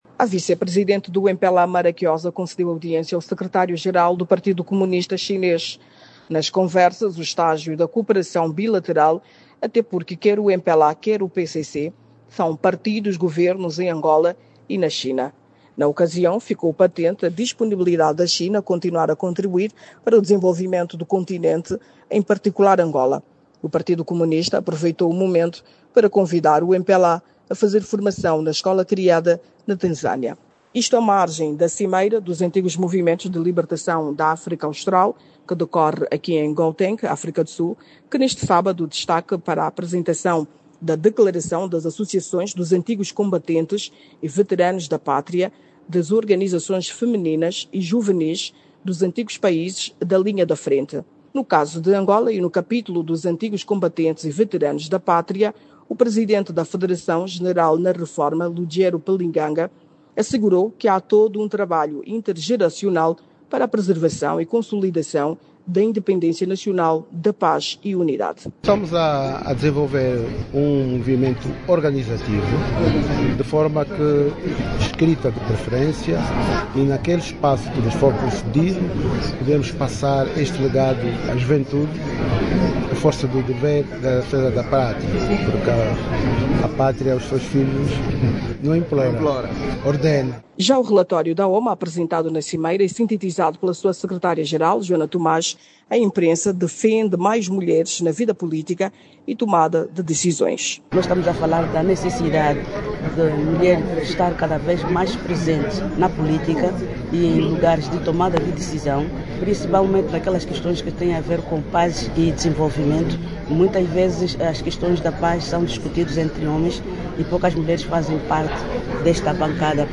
em Joanesburgo.